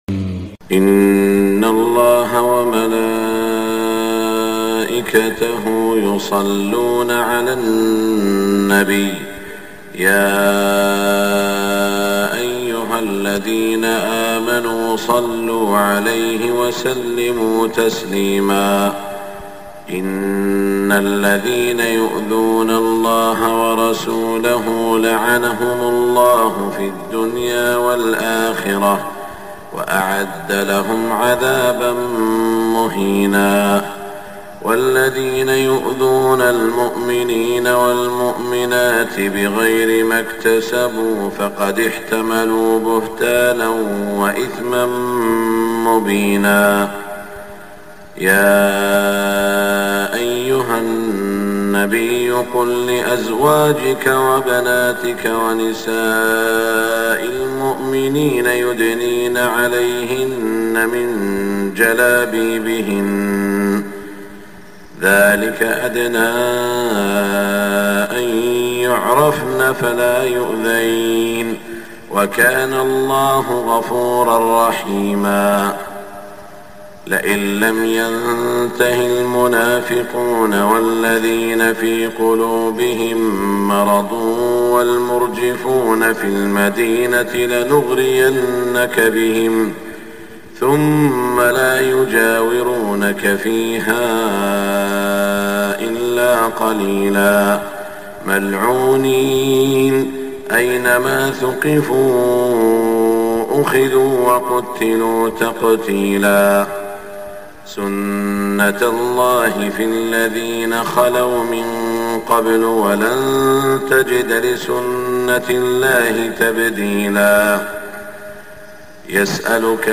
صلاة الفجر 1 ذو القعدة 1427هـ من سورة الأحزاب > 1427 🕋 > الفروض - تلاوات الحرمين